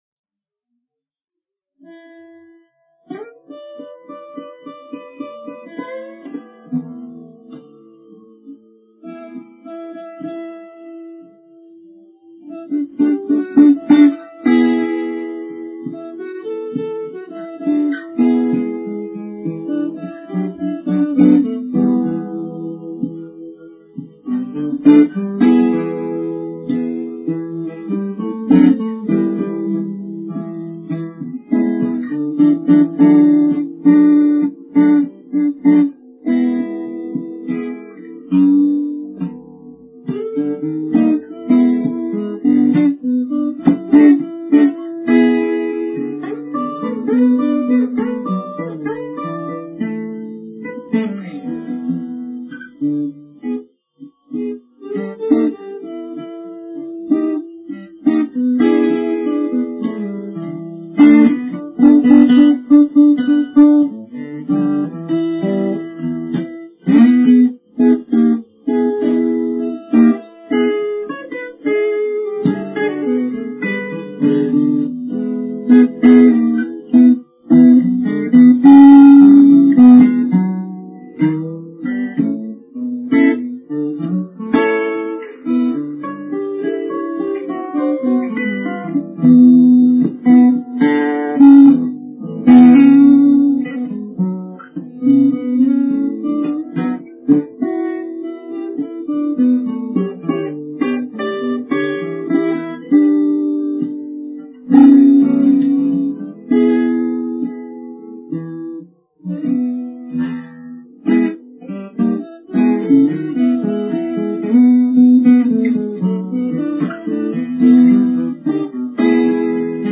rare raw cuts that won't ever lose their charm